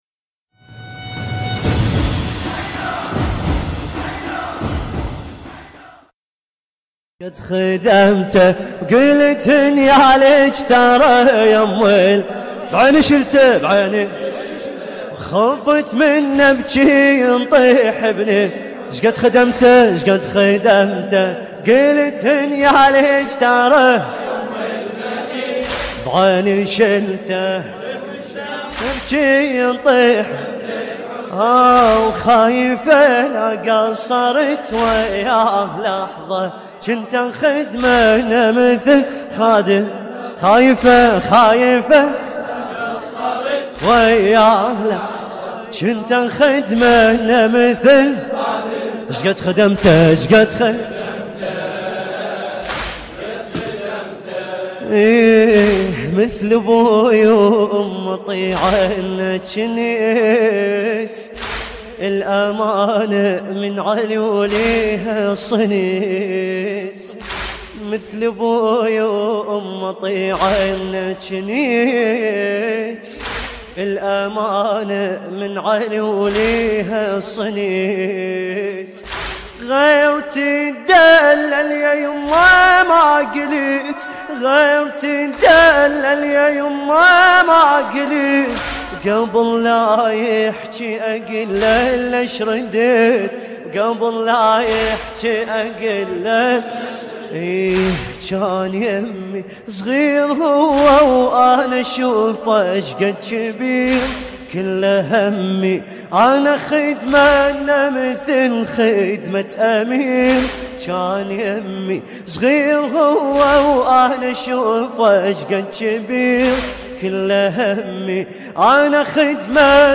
لطميات متفرقة